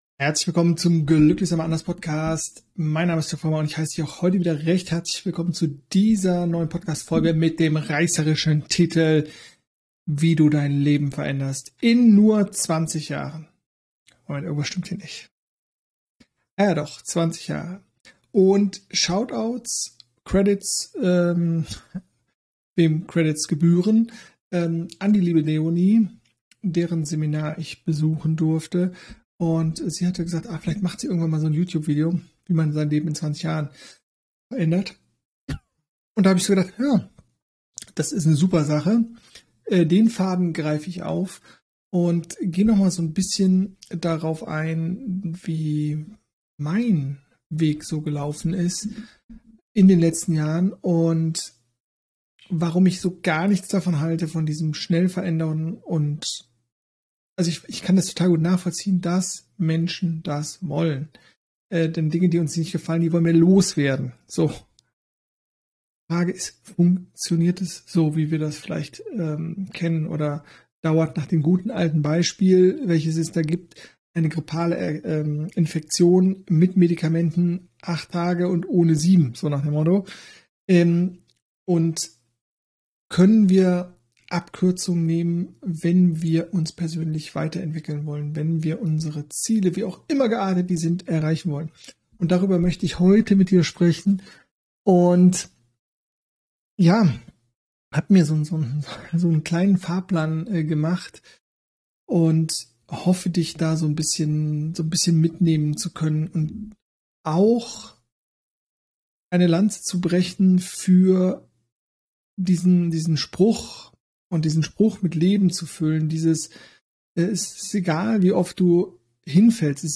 Deshalb schenke ich dir diese geführte Meditation mit der dein Körper, deine Seele und dein Geist zur Ruhe kommen dürfen.